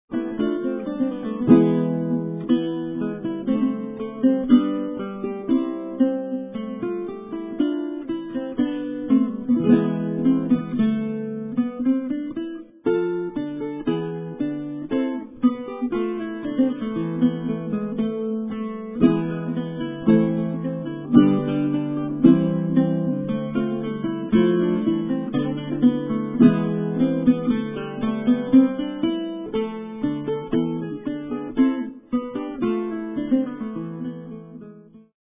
he plays the seldom heard Baroque guitar